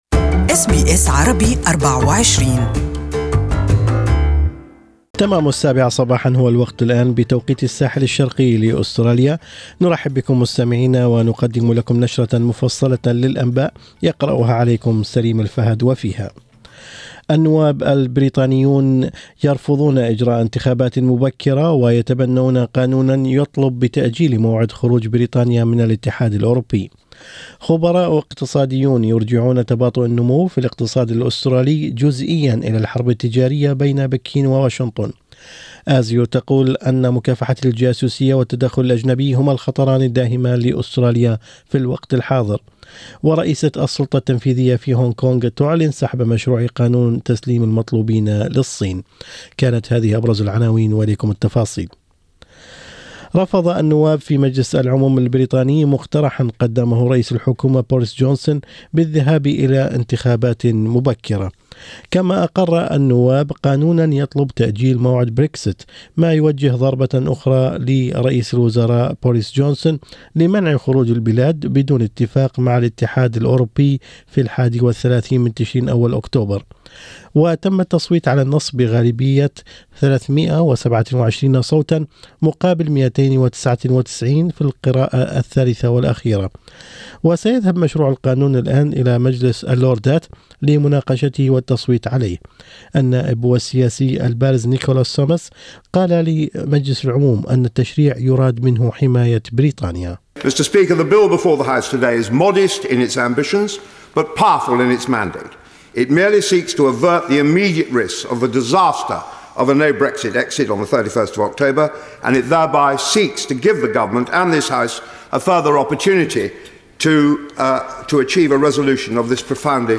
Morning News: ASIO chief labels foreign interference, espionage as most dangerous